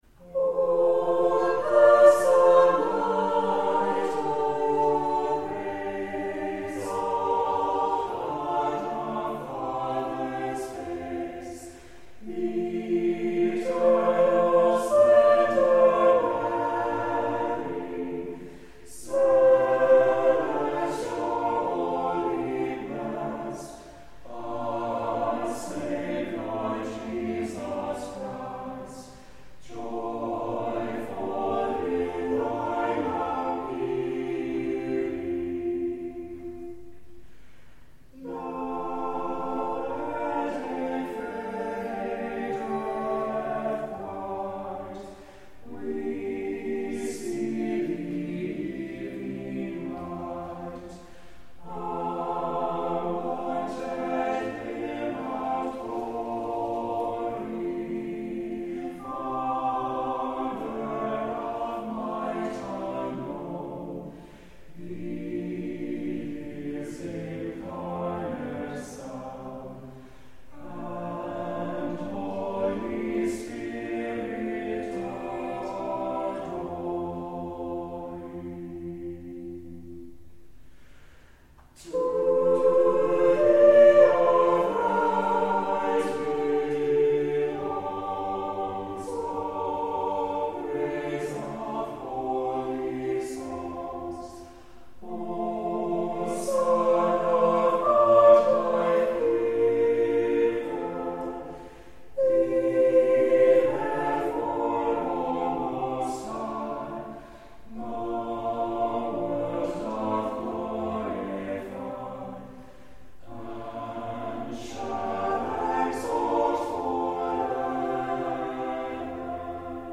Worship and Sermons from Christ Episcopal Church in Little Rock, Arkansas
On Sundays, virtual and in-person services of prayers, scripture, and a sermon are at 8 a.m., 10:30 a.m., and 6 p.m., and a sung service of Compline begins at 7 p.m.
Christ Church Audio Home Categories Admin Compline 2025-02-02 The Compline Choir Download Size: 13 MB 1 Powered by Podcast Generator , an open source podcast publishing solution | Theme based on Bootstrap